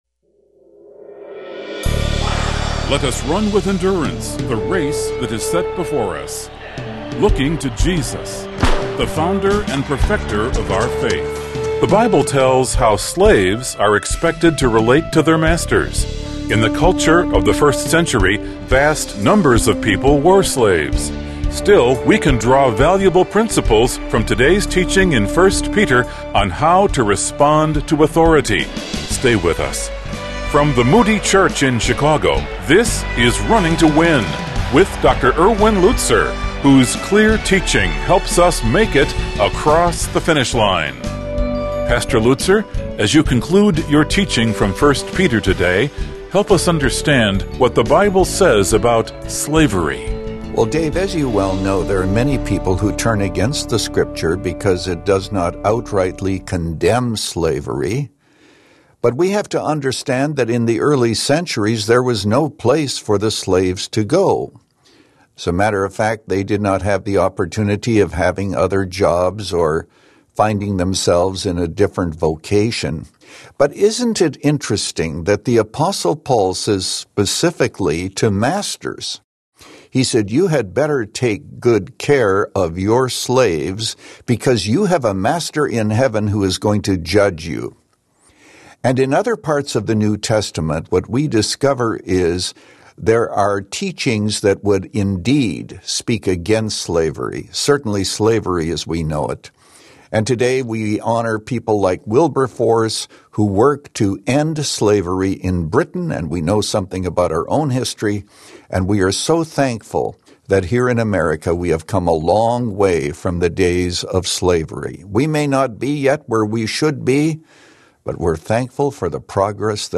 According to Pastor Erwin Lutzer, it includes doing good and suffering for it. In this message from 1 Peter 2, Pastor Lutzer invites us to act, believe, and sacrifice like Jesus—even in the face of injustice.